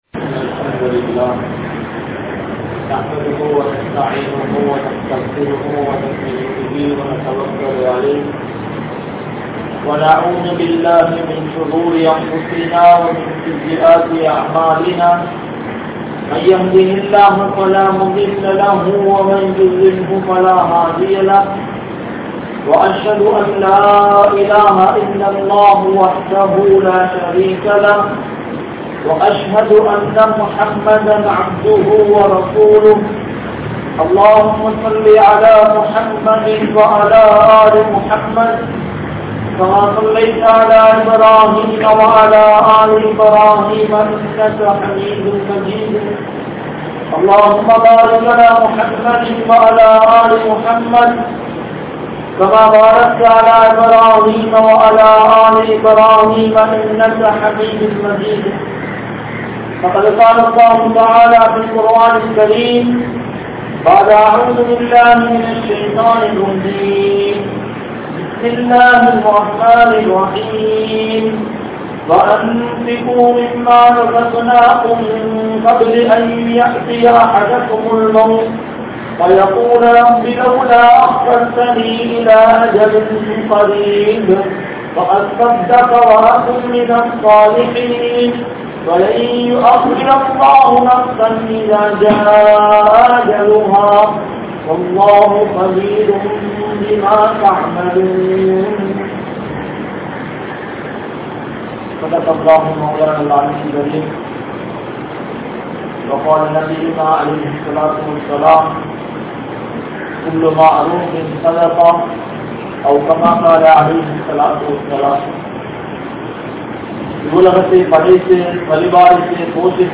Sadhaqa & Al Quran | Audio Bayans | All Ceylon Muslim Youth Community | Addalaichenai